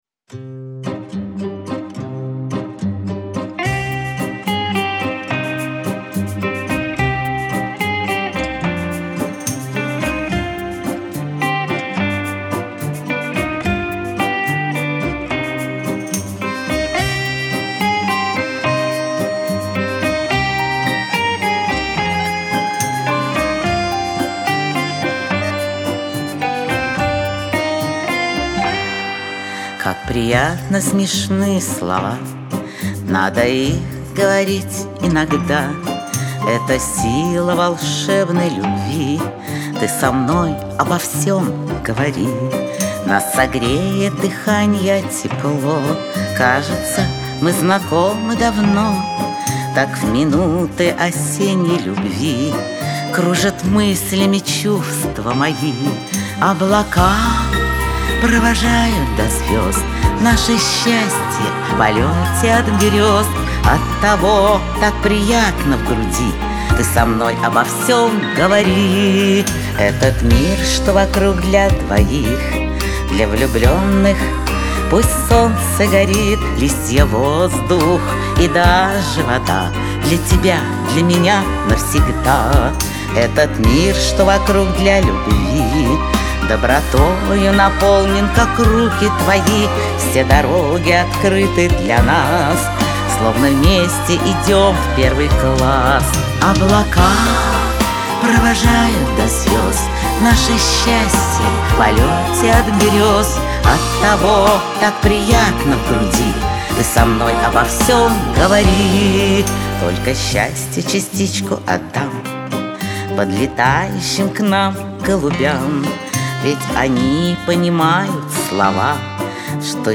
Лирика , эстрада
Шансон